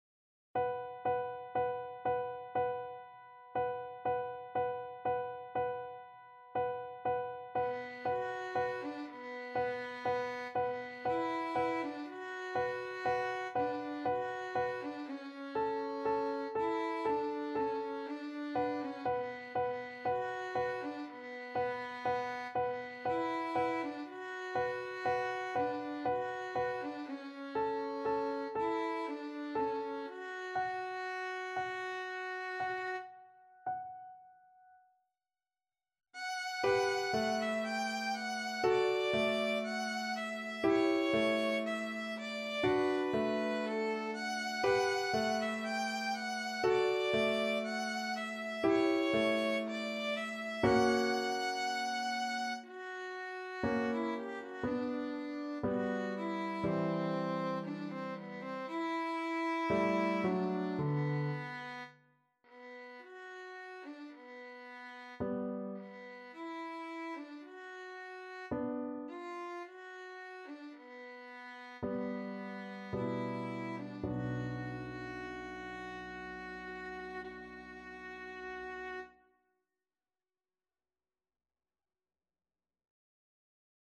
Violin
3/4 (View more 3/4 Music)
B4-G6
B minor (Sounding Pitch) (View more B minor Music for Violin )
Andante sostenuto =60
Classical (View more Classical Violin Music)